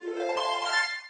chime_4.ogg